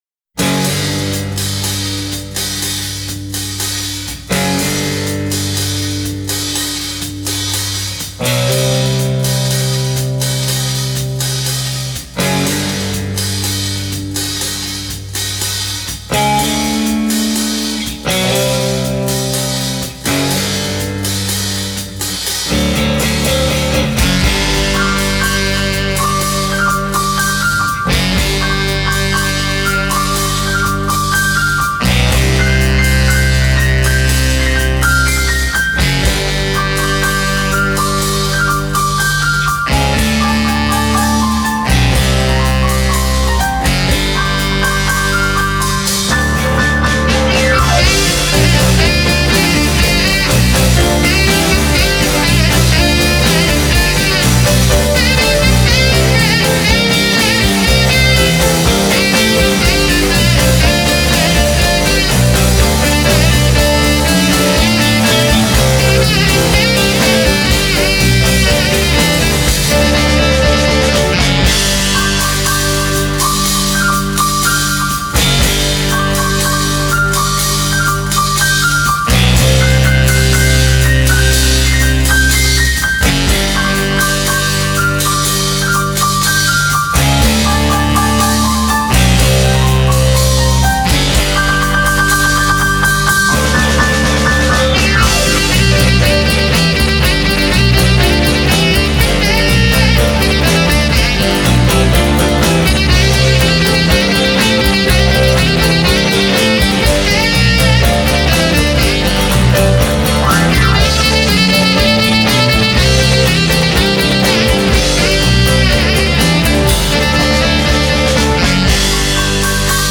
an American instrumental rock band
Genre: Instrumental